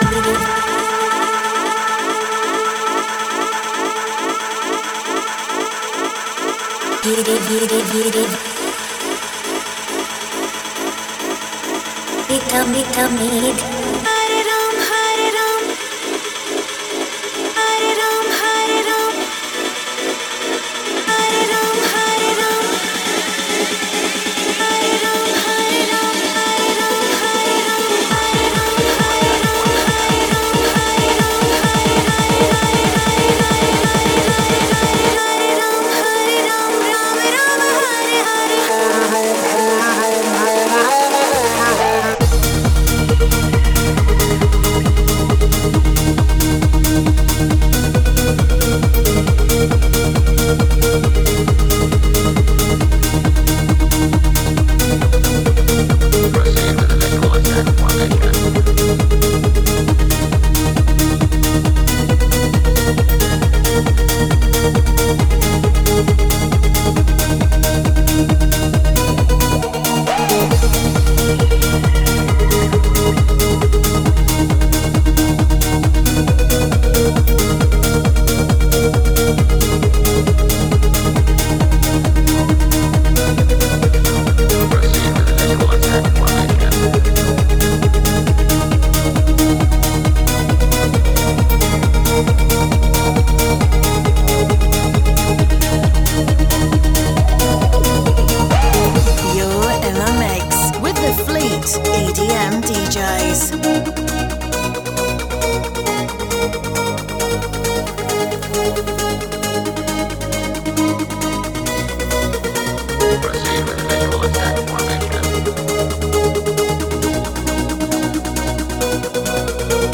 Genre: House, Electronic, Dance.